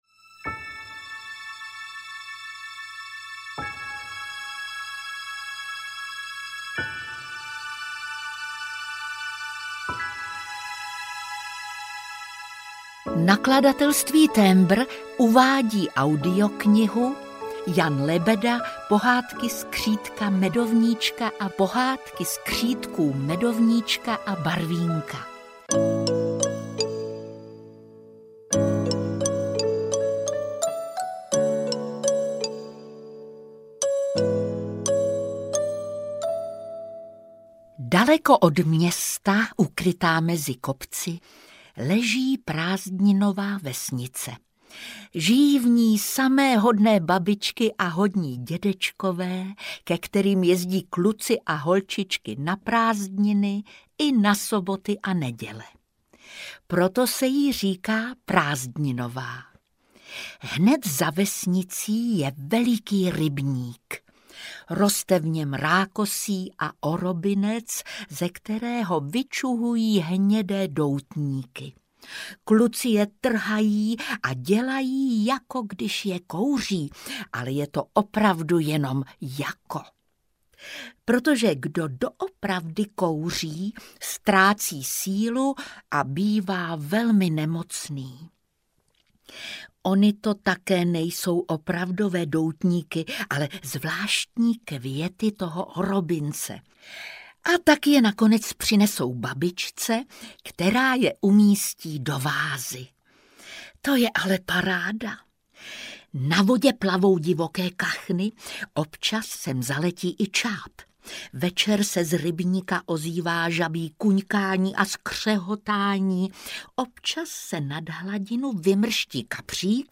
Pohádky skřítka Medovníčka a Pohádky skřítků Medovníčka a Barvínka audiokniha
Ukázka z knihy
• InterpretJitka Molavcová